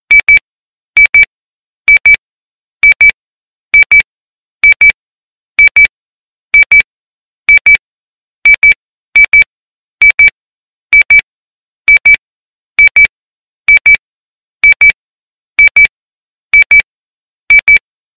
nextel-high-gai_25222.mp3